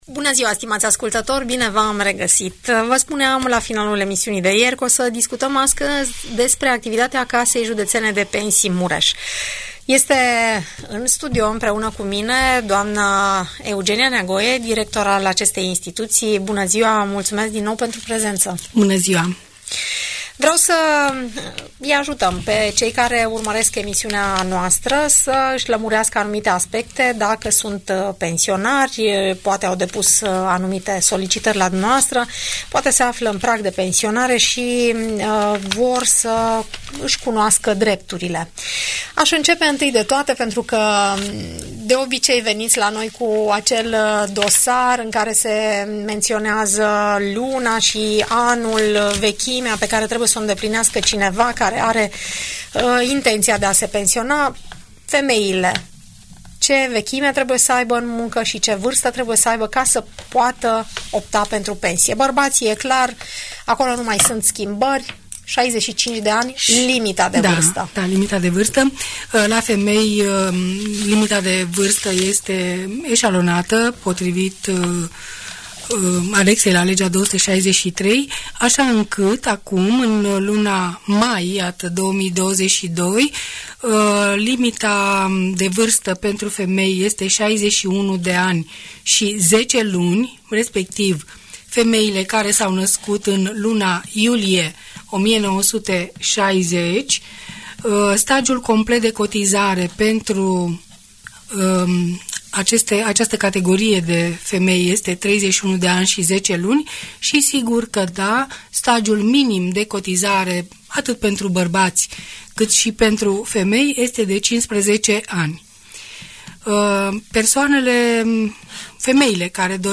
Audiență radio pentru pensionari